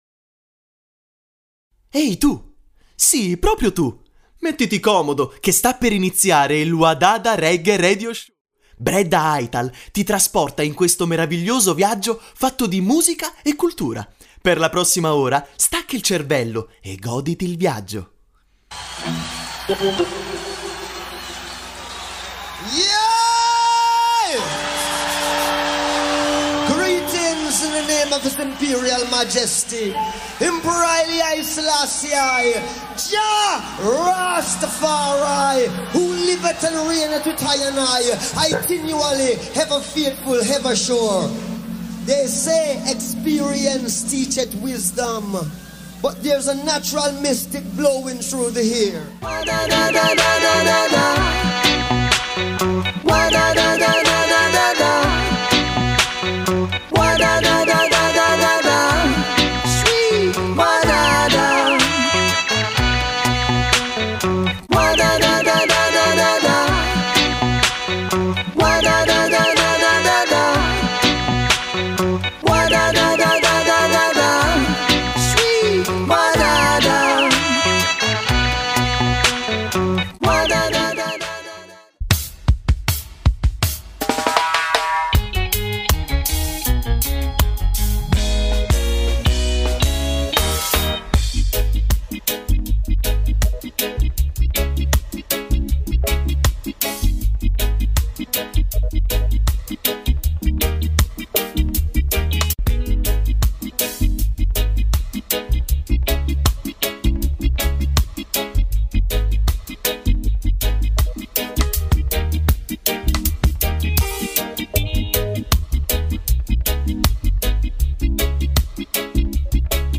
Roots Reggae Dub Music